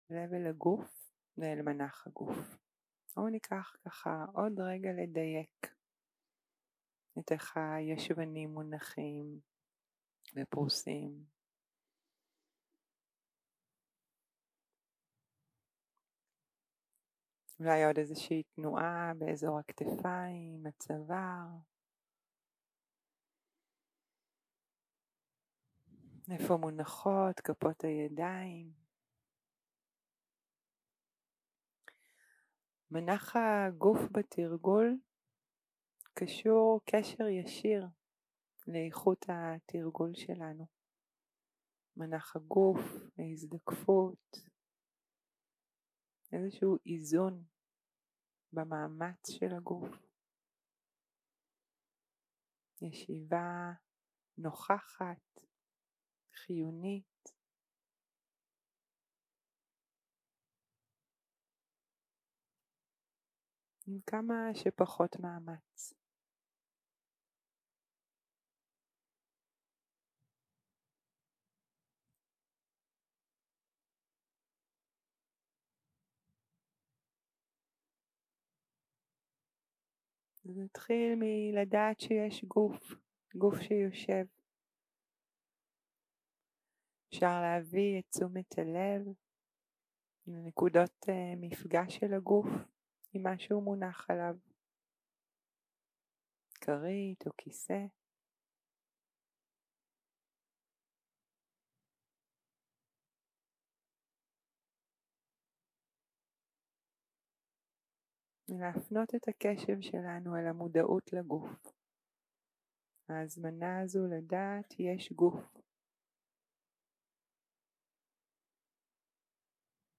יום 2 - הקלטה 2 - צהרים - מדיטציה מונחית - נשימה, גוף, התרווחות.
0:00 0:00 סוג ההקלטה: סוג ההקלטה: מדיטציה מונחית שפת ההקלטה: שפת ההקלטה: עברית